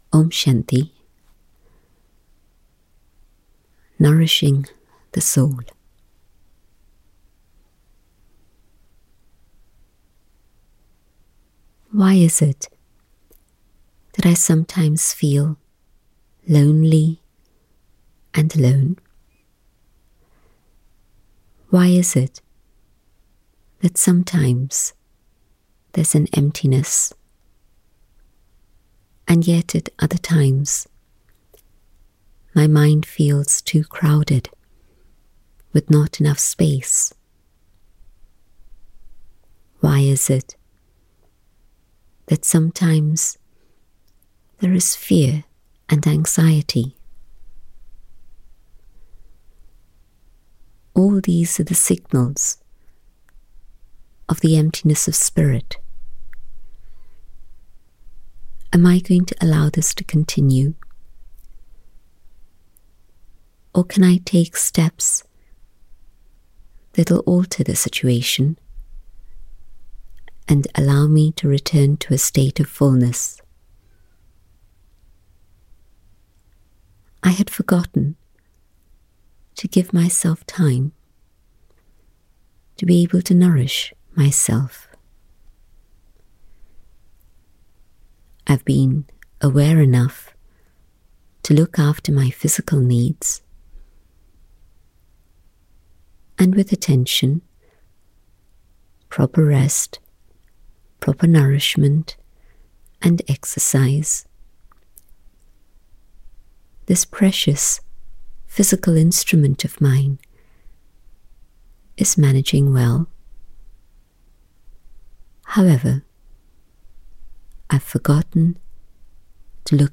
Downloadable Meditations